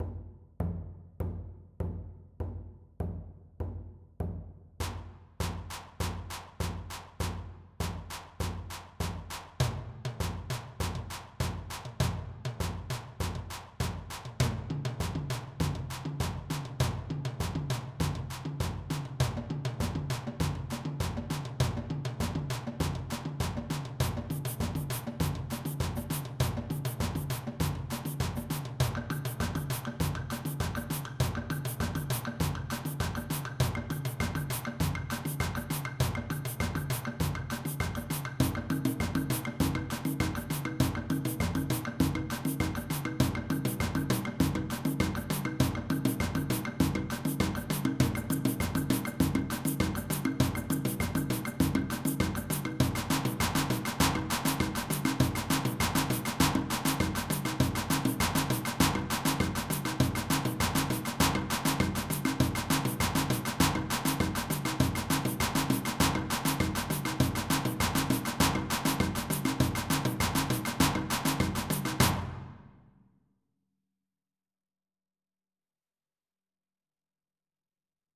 Balinese Kecak (Monkey Chant)
Kecak @ 200